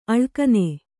♪ aḷkane